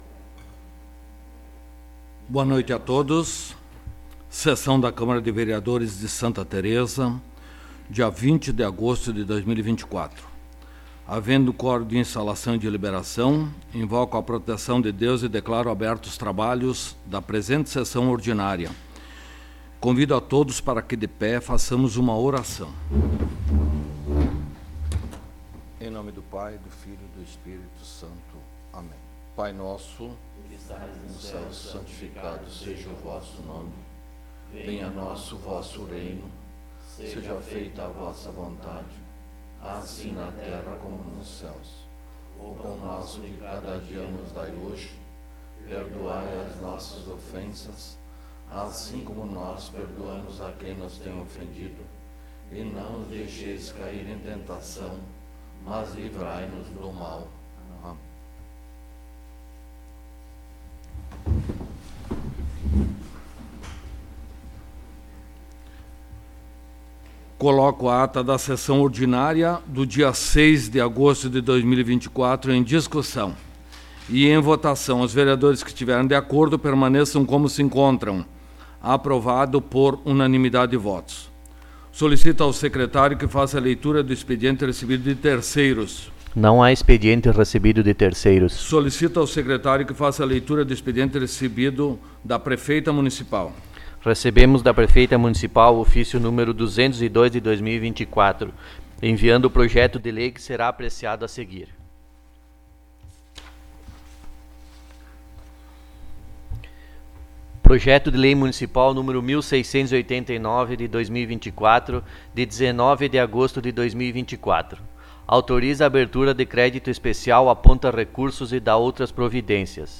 14ª Sessão Ordinária de 2024
Áudio da Sessão
Local: Câmara Municipal de Vereadores de Santa Tereza